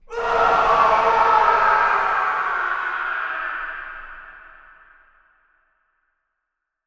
Zdeath.wav